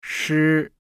• Sh: Không bật hơi. Vị trí lưỡi giống âm zh, âm phát ra nhẹ nhàng tạo tiếng “xì xì”, mượt mà.
Âm phát ra tạo tiếng “xì xì”.
6.3.-sh-nam.mp3